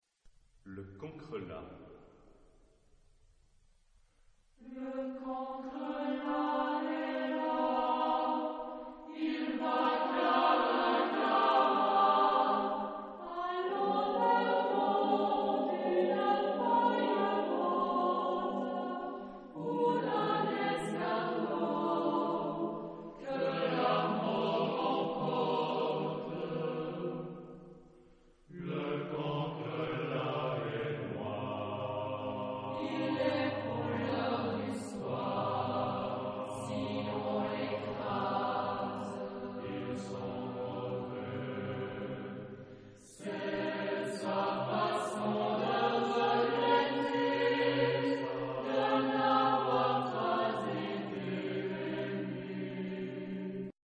Genre-Style-Form: Choral suite ; Partsong ; Poem ; Secular
Mood of the piece: humorous ; sombre ; calm
Type of Choir: SATB  (4 mixed voices )